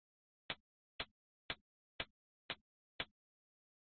hi hat 09
描述：hi hat
Tag: 镲片 hi_hat Rides